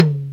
Tom-03.wav